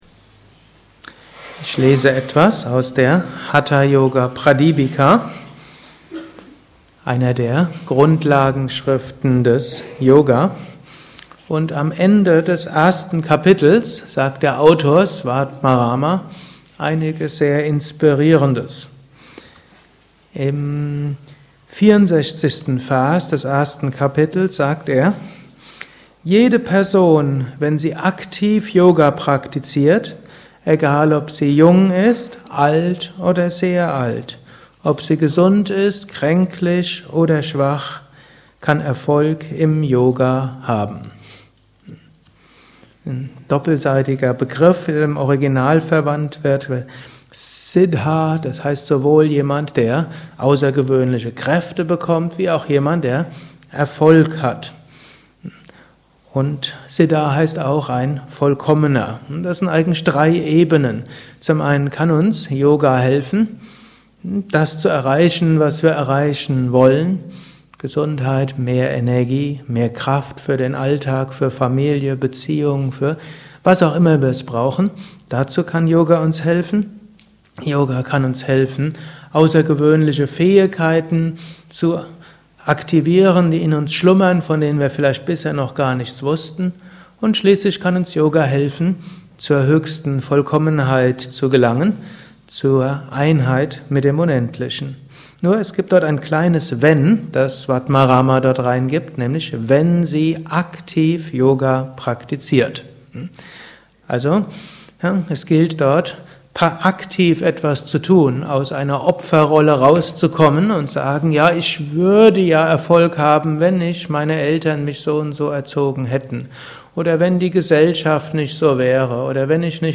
Morgenvortrag nach der Meditation.